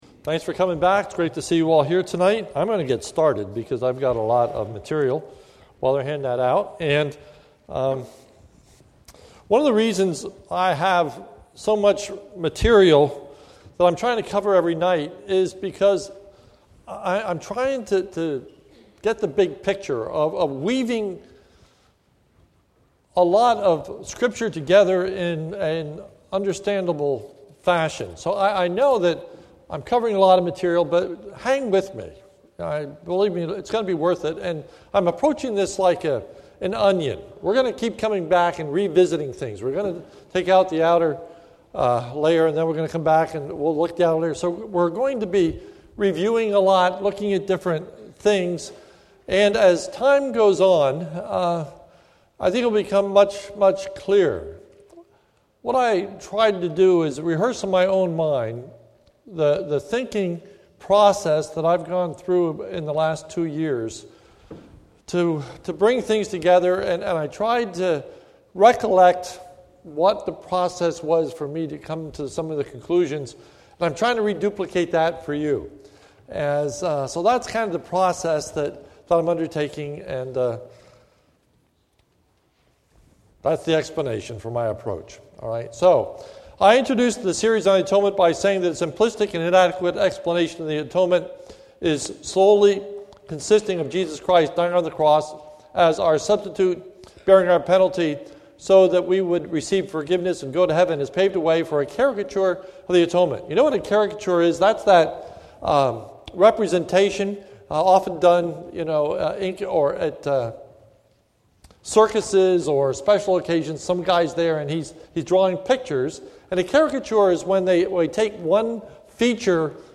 This is a sermon recorded at the Lebanon Bible Fellowship Church, in Lebanon, PA, on 4/6/2014 during the evening service